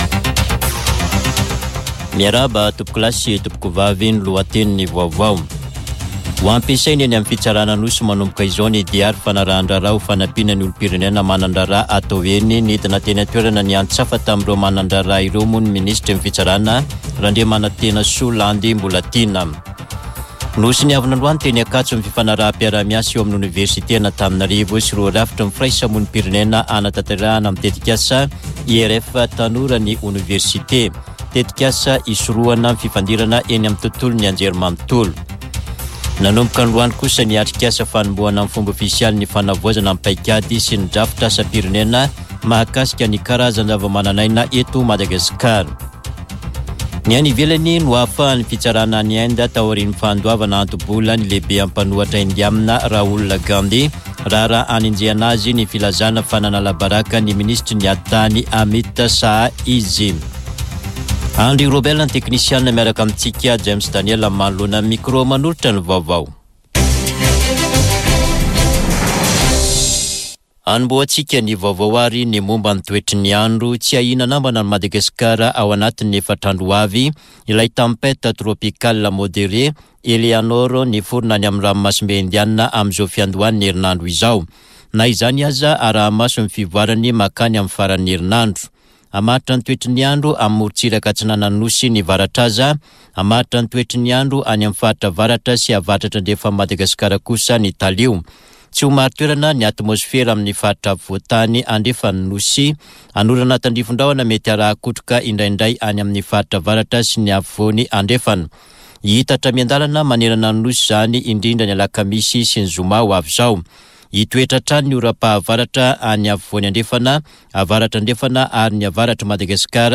[Vaovao hariva] Talata 20 febroary 2024